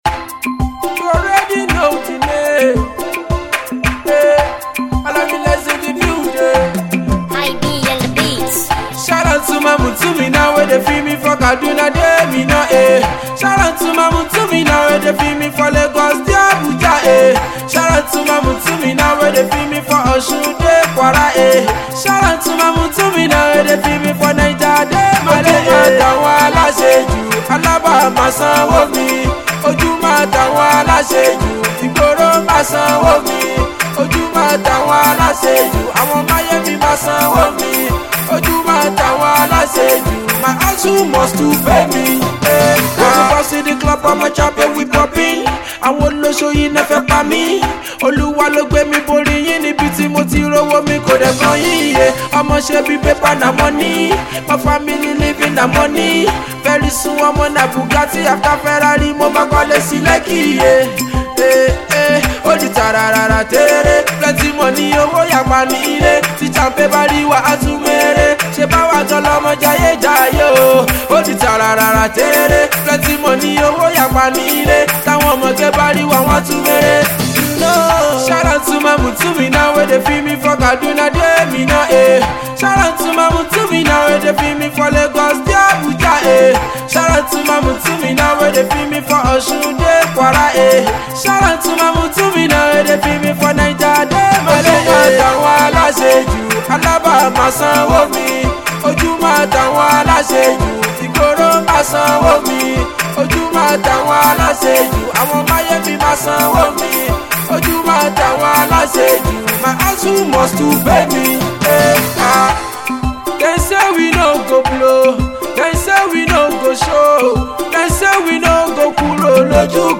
Freestyle